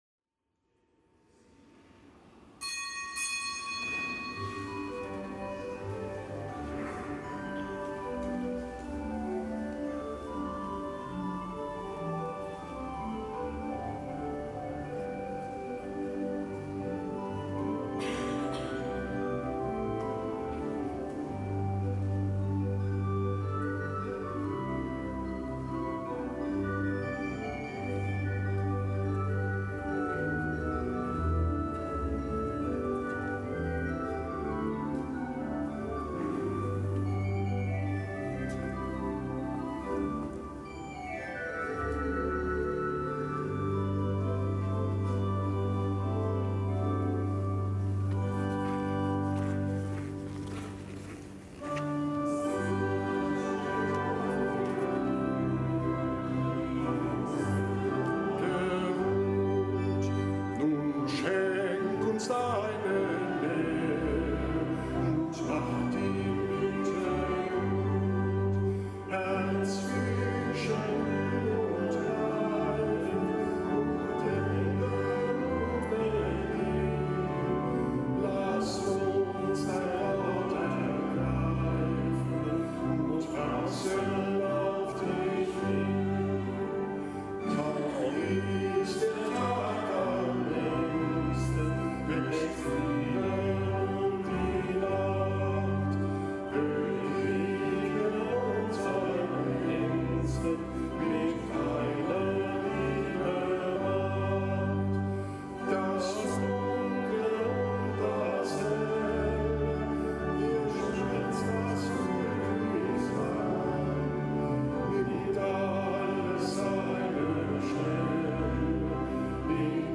Kapitelsmesse am Dienstag der elften Woche im Jahreskreis
Kapitelsmesse aus dem Kölner Dom am Dienstag der elften Woche im Jahreskreis